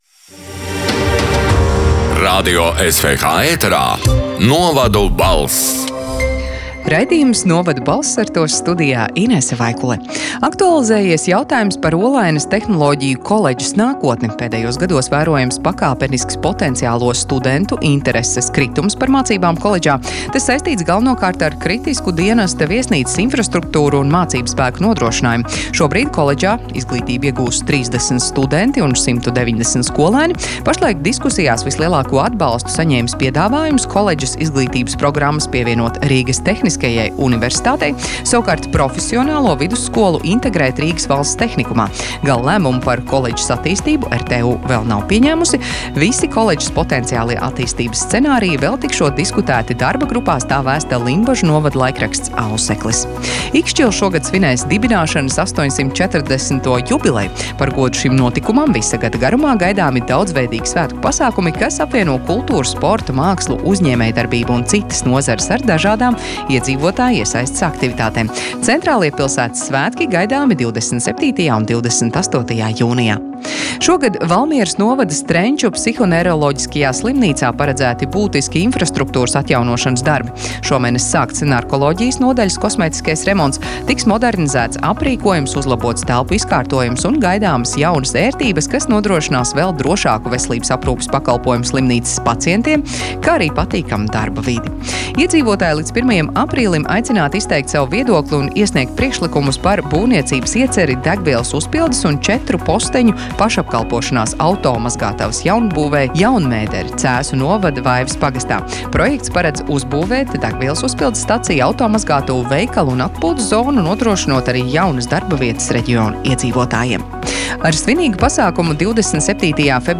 “Novadu balss” 5. marta ziņu raidījuma ieraksts: